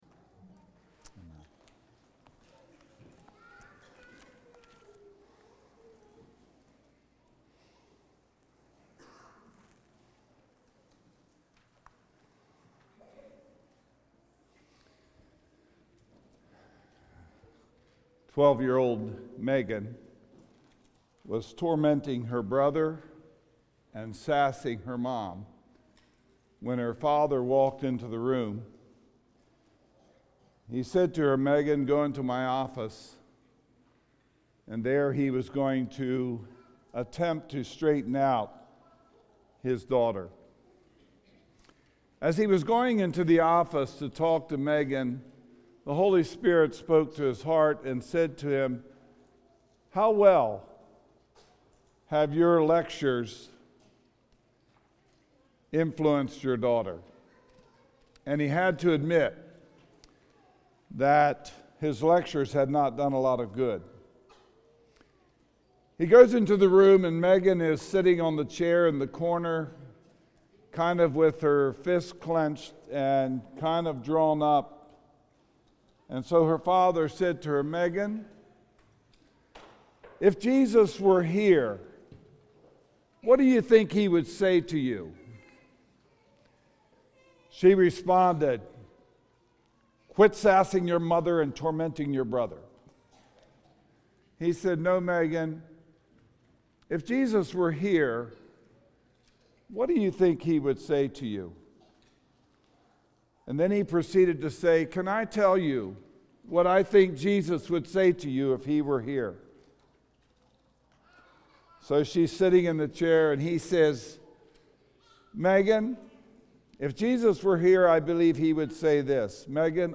Sermons | Christian Life Mennonite
Revival Meeting Sunday Morning